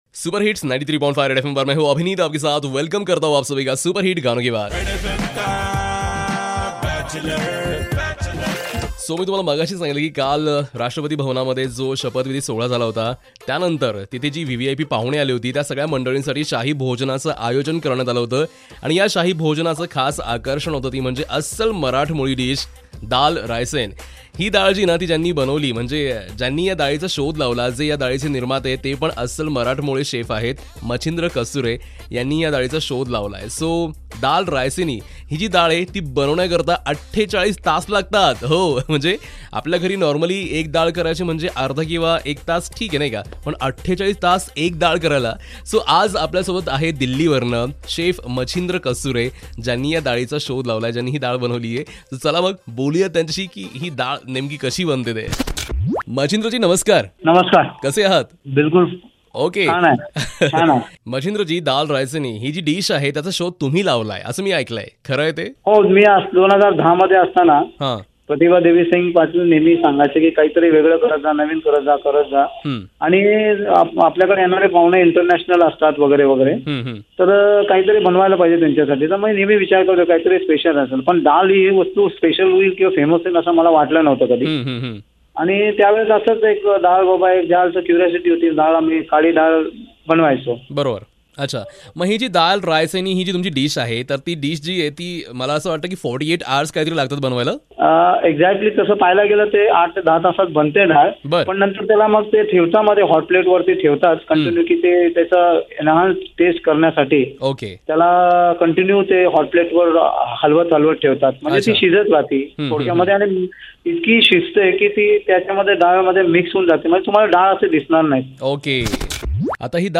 taking an interview of chief